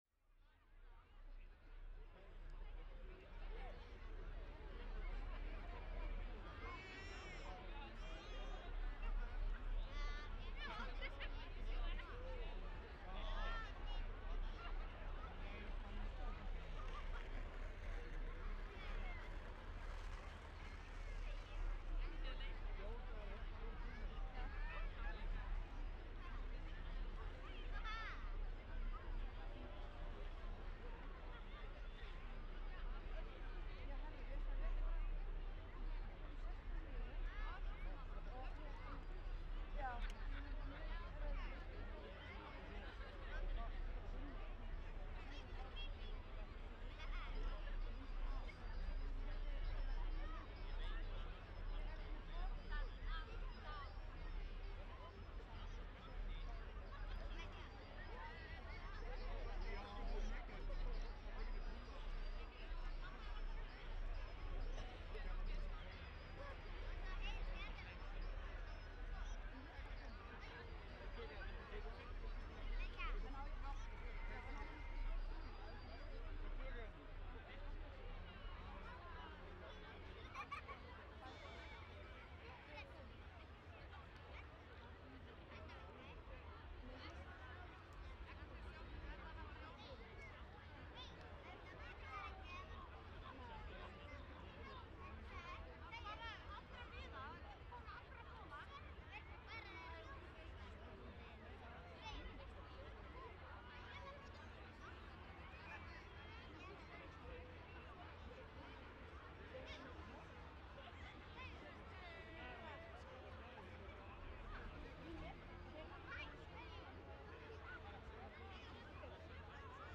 Fireworks at Reykjavik Culture Festival 2014
But this recording was made in the end of the Reykjavik Culture Nights at 23rd of August 2014 when one third of the Icelandic population was in down town Reykjavik in a wonderful weather.
This recording was a „ Binaural array test“.
It is a pair of MKH20 microphones in a single Rycote stereo windshield which I hope will give a better result than AB40 array with BBG Rycote windshield.
Fireworks are probably not the best sound source for such test, but anyway MKH20 is a grate sounding mic and in this case it was important to detect sound from all directions to pick up the echo from buildings all around. The rumble bass and the calm music in the background is coming from the concert stage. The fireworks start slowly but end in total madness.